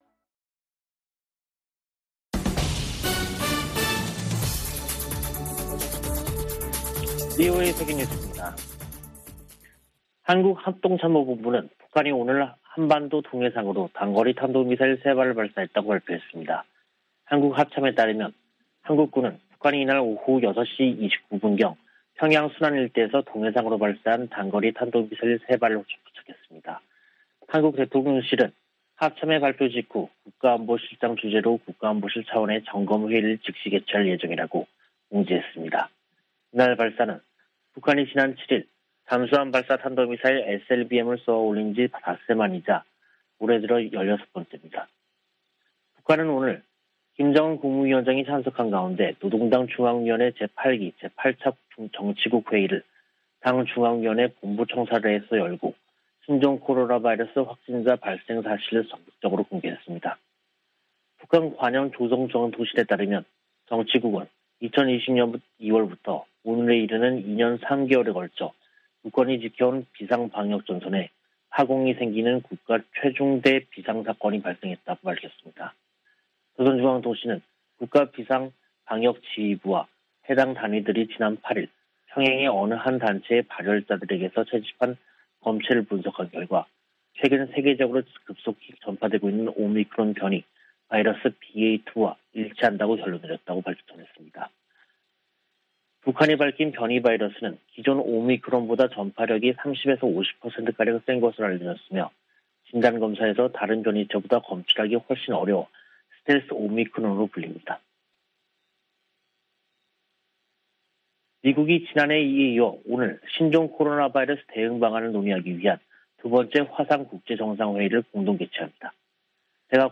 VOA 한국어 간판 뉴스 프로그램 '뉴스 투데이', 2022년 5월 12일 3부 방송입니다. 북한이 또다시 탄도미사일을 발사했습니다.